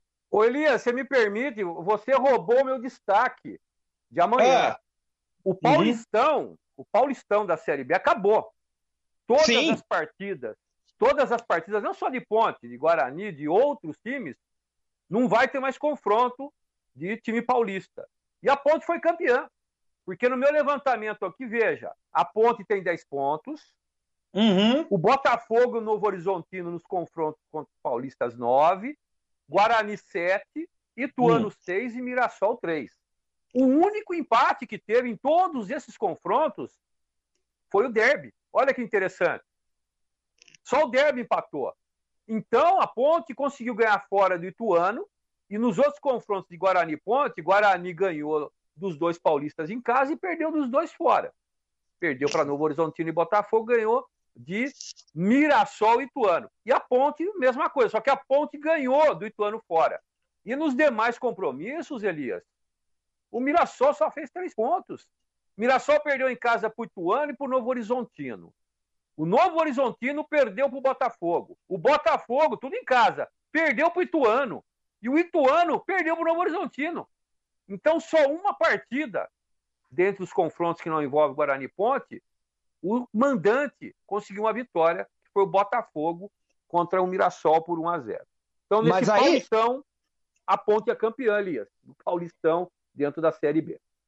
Comentaristas da Rádio Brasil analisam as movimentações da janela de transferências